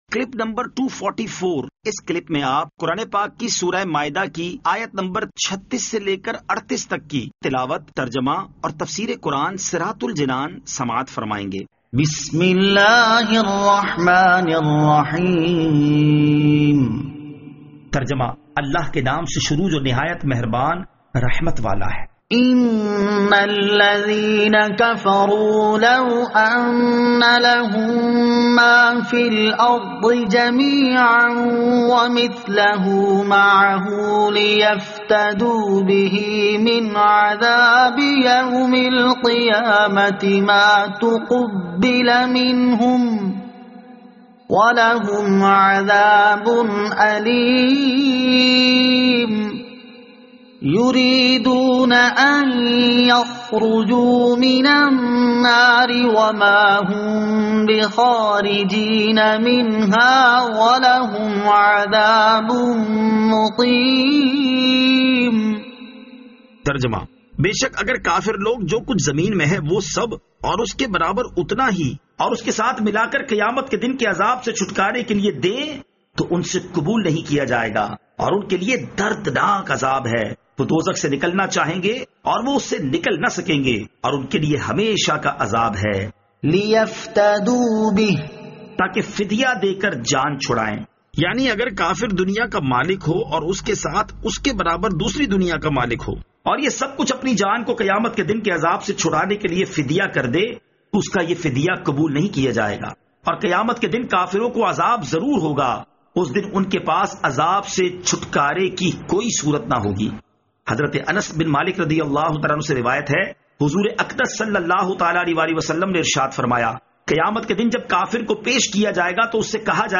Surah Al-Maidah Ayat 36 To 38 Tilawat , Tarjama , Tafseer